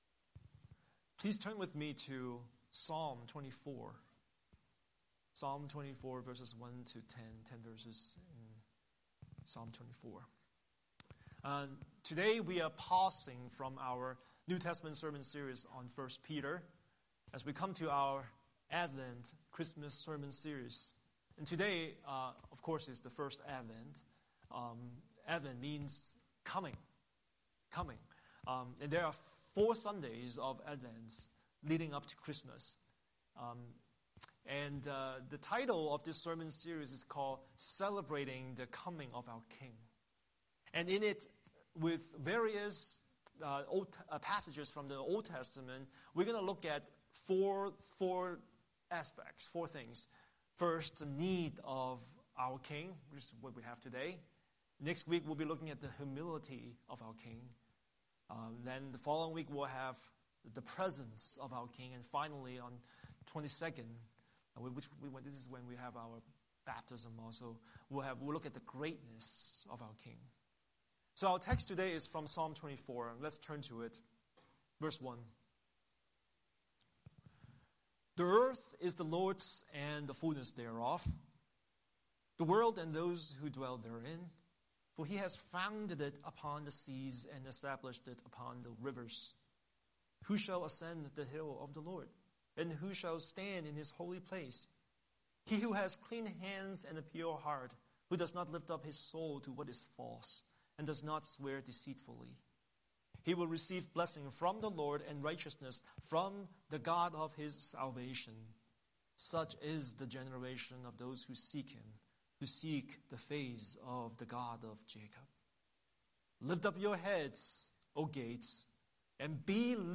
Scripture: Psalm 24:1–10 Series: Sunday Sermon